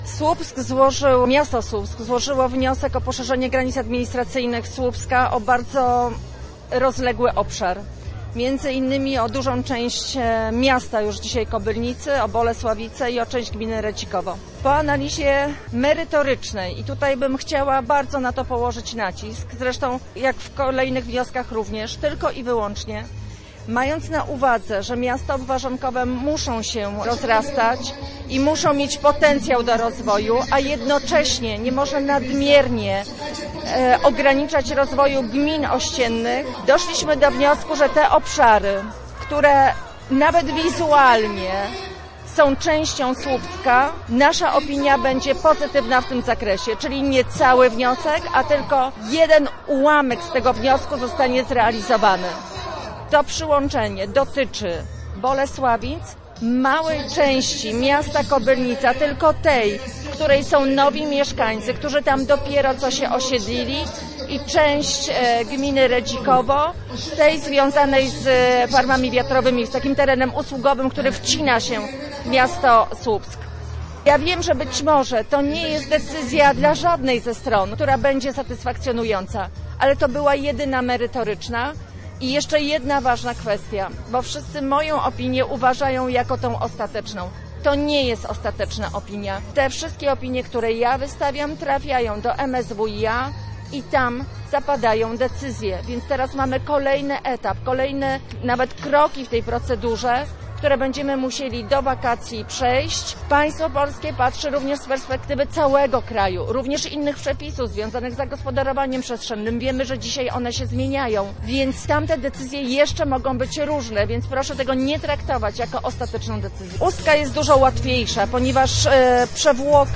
Posłuchaj materiału reporterki: